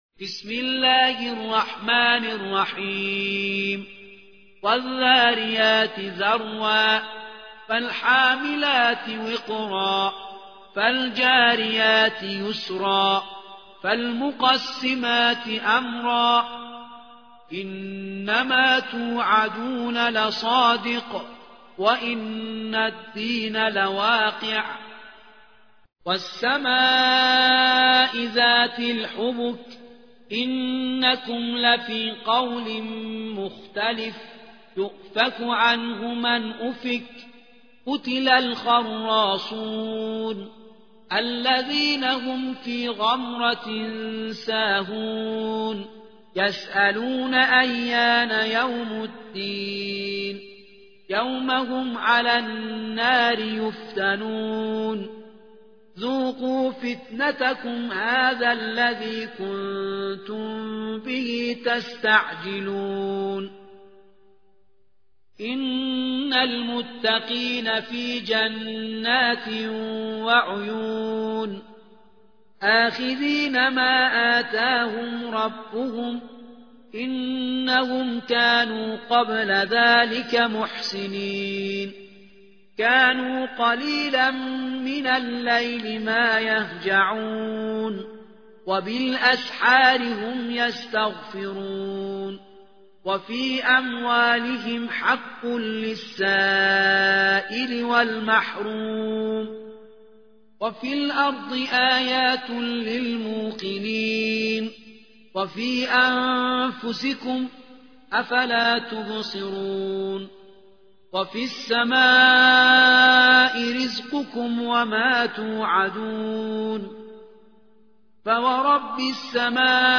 51. سورة الذاريات / القارئ